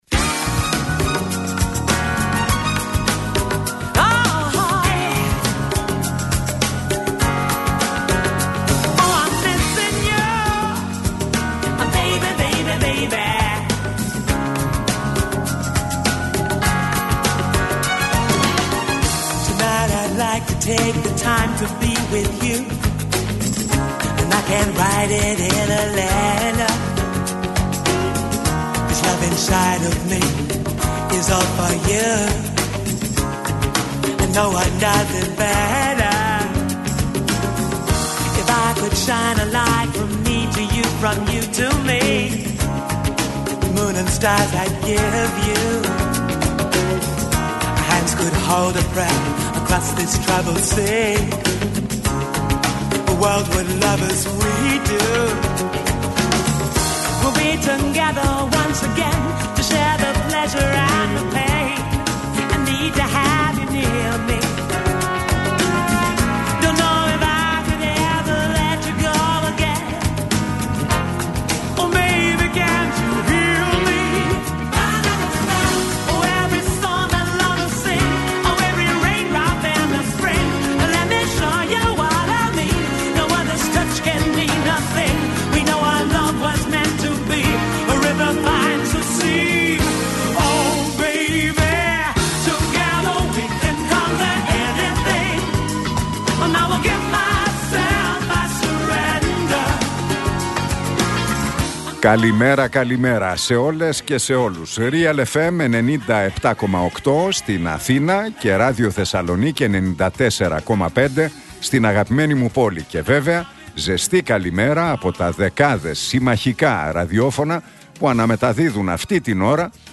Ακούστε την εκπομπή του Νίκου Χατζηνικολάου στον RealFm 97,8, την Δευτέρα 15 Ιανουαρίου 2024.